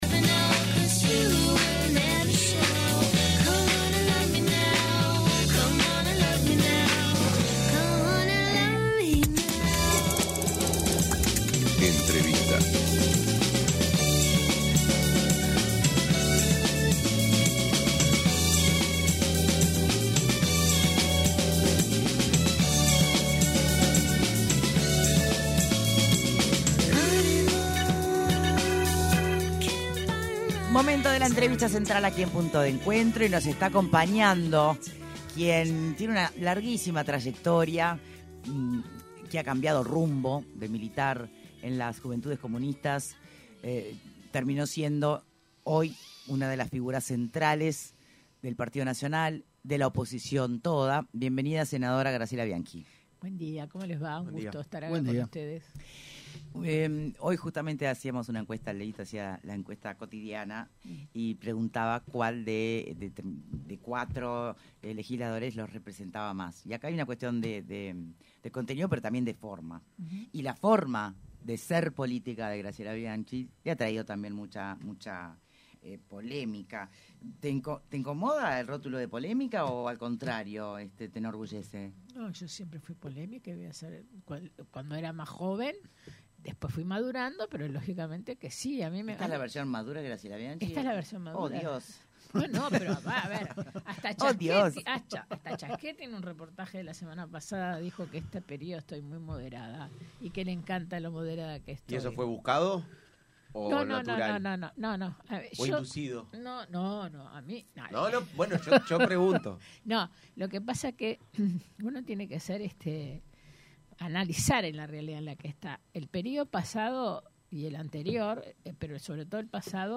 La senadora del Partido Nacional Graciela Bianchi, reveló en entrevista con Punto de Encuentro que el exministro de Salud Pública, Daniel Salinas -que se había manejado como posible compañero de fórmula de Álvaro Delgado- había aceptado ser candidato a vicepresidente en las elecciones de 2024. Sin embargo, Delgado tenía en mente desde el primer momento a Valeria Ripoll, lo que fue un “error”, dijo Bianchi.